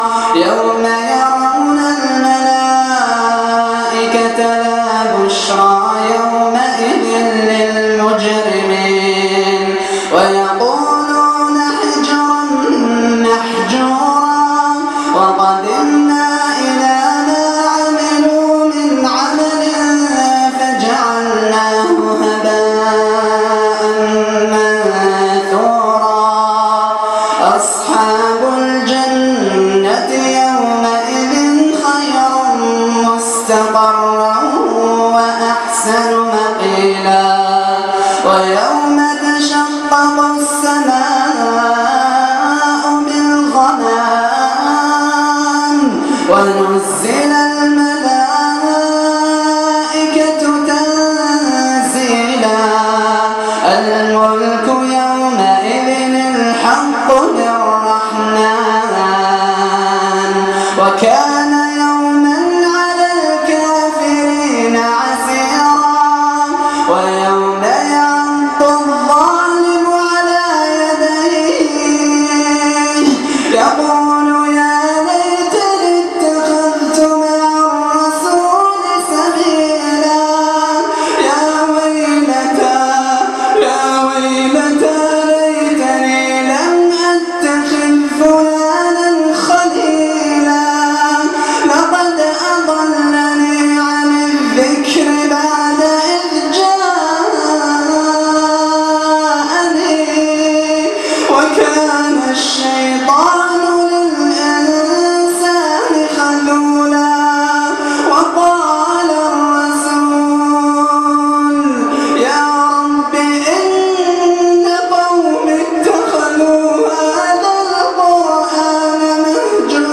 من صلاة التراويح